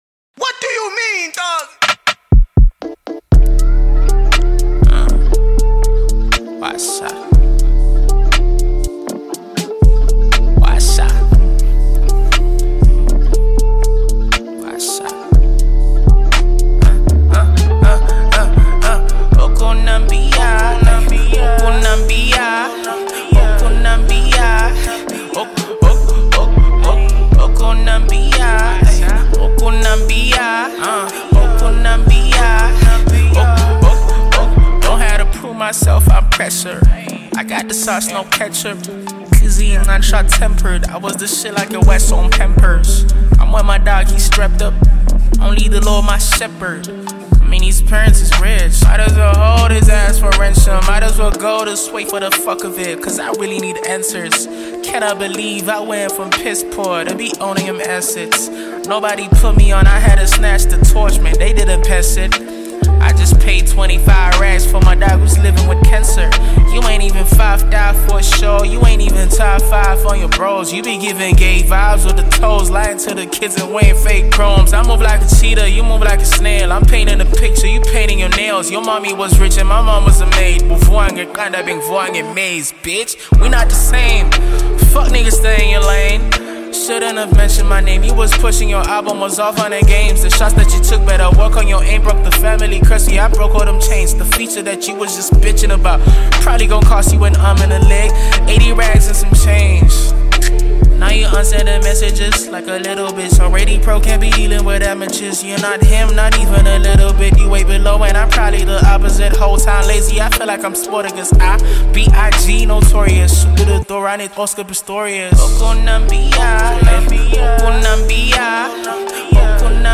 strong vocals, emotional depth